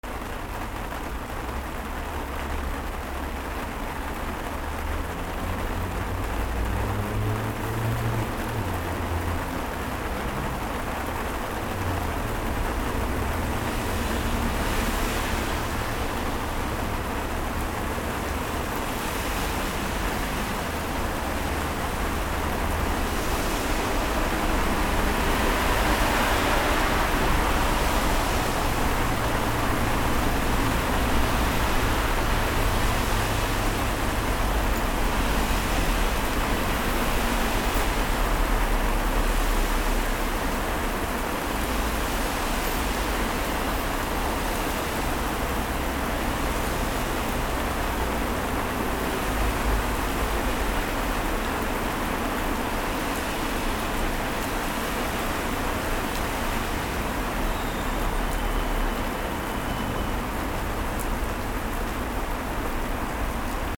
雨 道路
/ A｜環境音(天候) / A-30 ｜雨 道路
ザーー